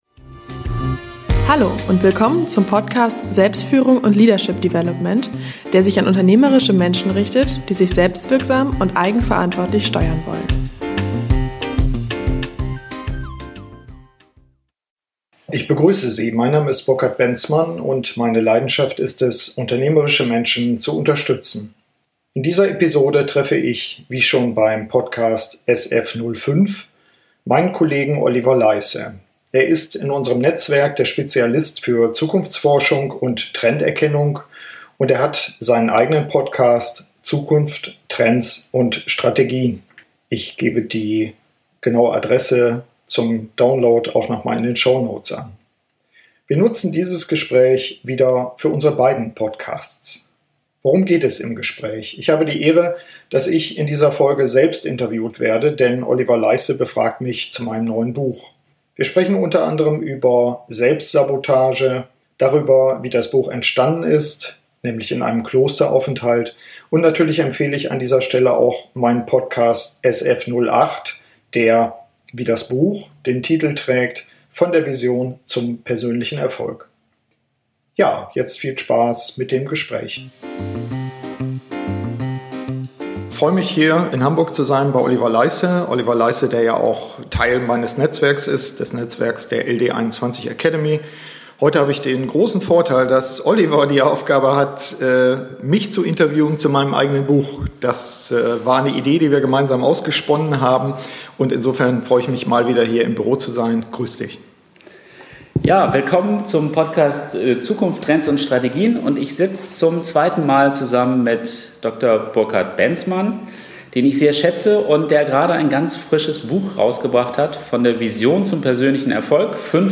SF12 Interview zum neuen Kompaktbuch ~ Selbstführung und Leadership Development Podcast